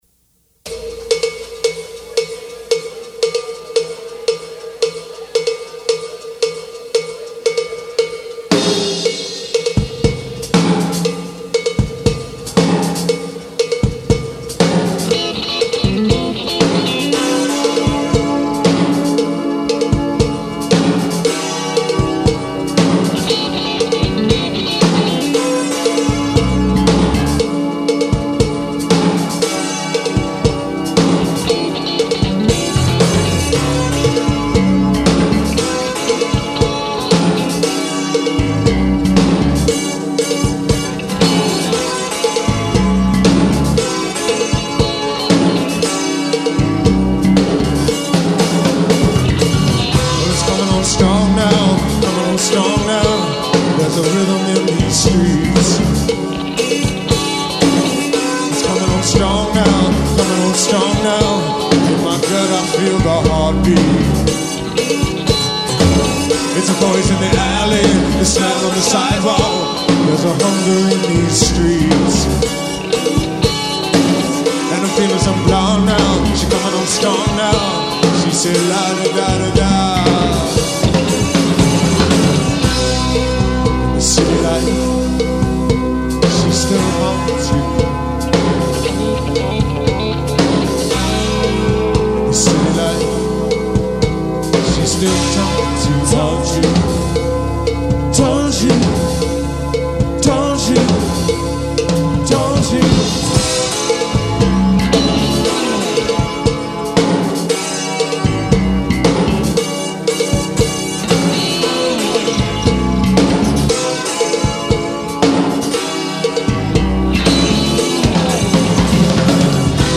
The Live stuff